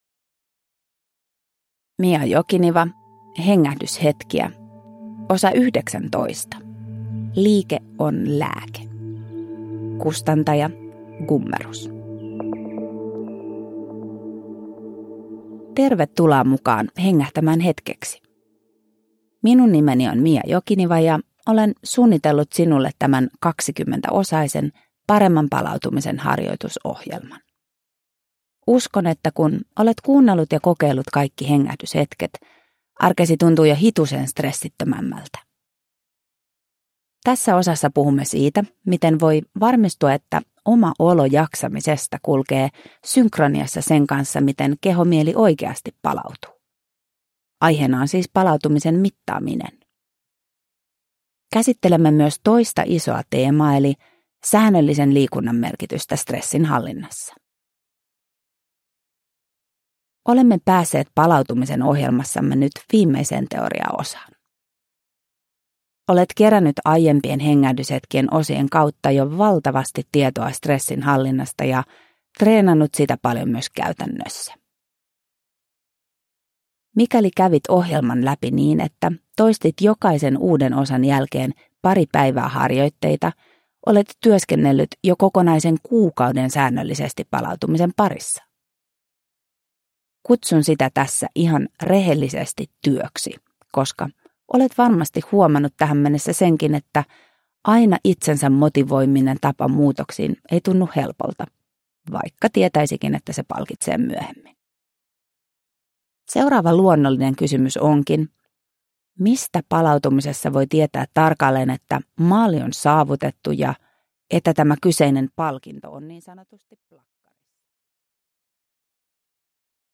Hengähdyshetkiä (ljudbok